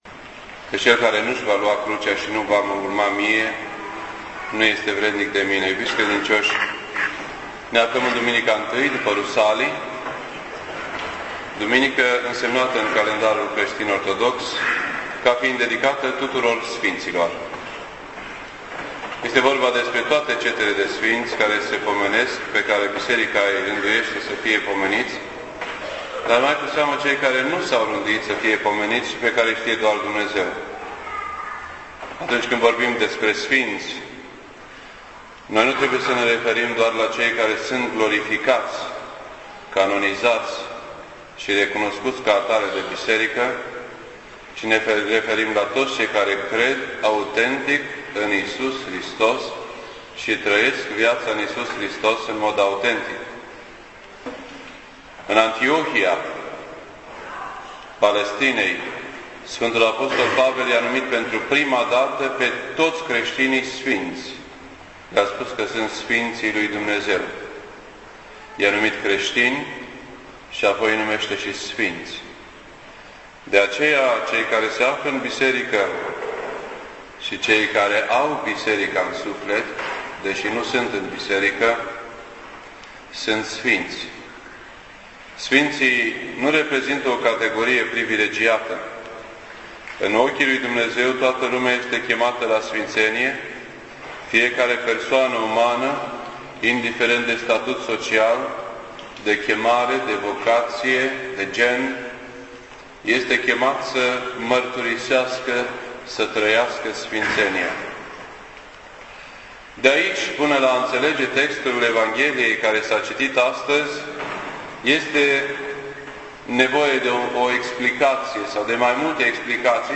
This entry was posted on Sunday, June 14th, 2009 at 6:44 PM and is filed under Predici ortodoxe in format audio.